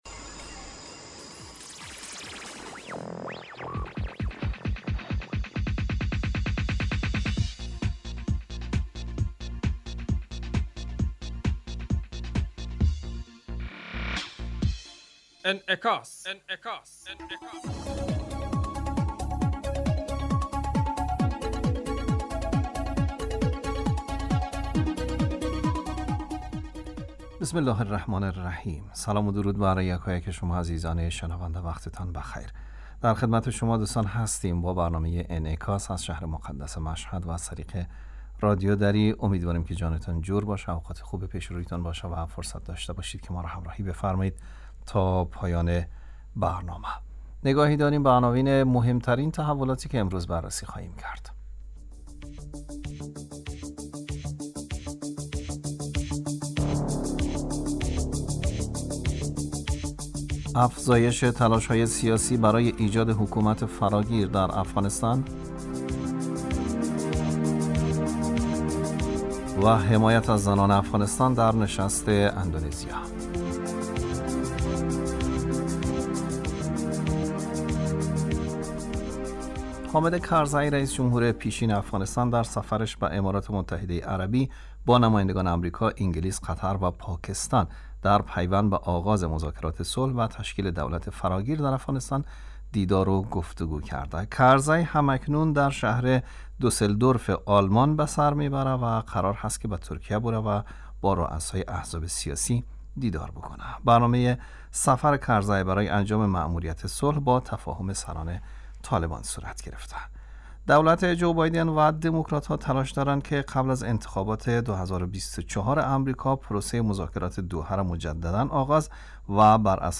برنامه انعکاس به مدت 35 دقیقه هر روز در ساعت 06:50 بعد ظهر (به وقت افغانستان) بصورت زنده پخش می شود.